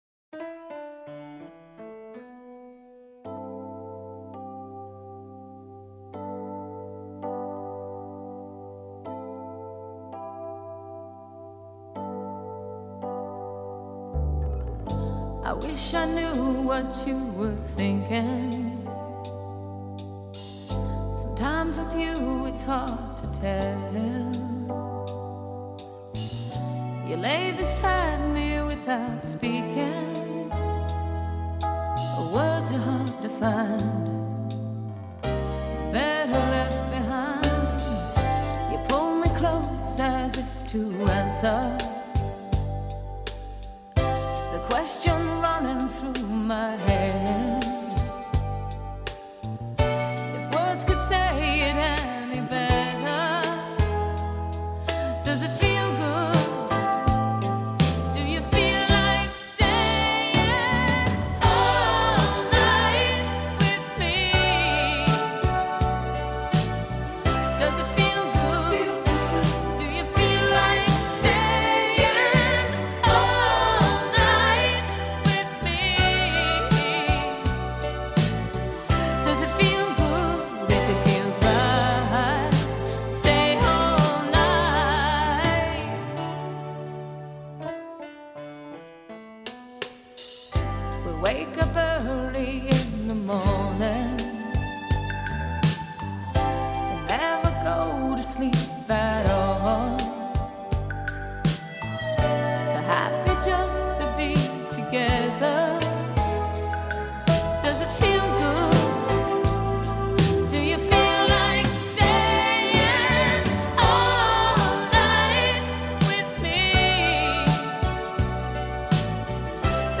Pop, Dance-Pop